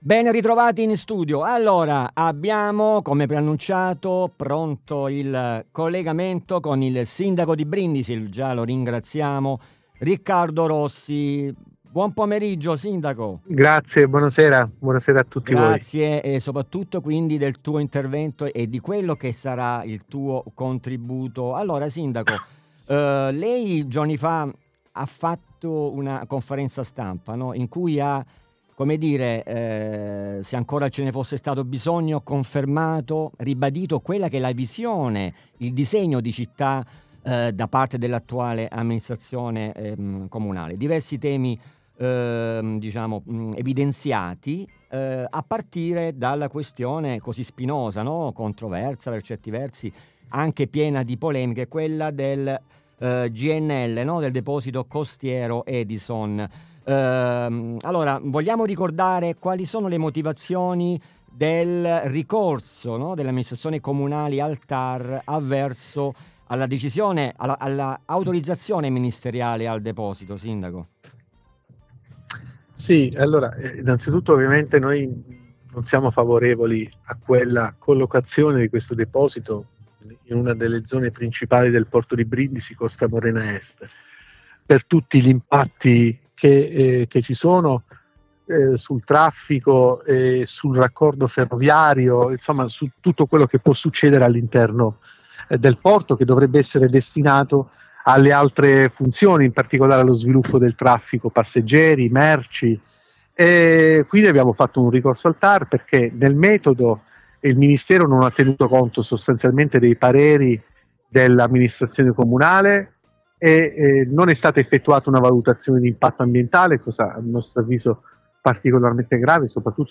Ospite in collegamento: Riccardo Rossi, Sindaco di Brindisi.